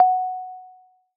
zapsplat_multimedia_alert_notification_mallet_chime_001_45041.mp3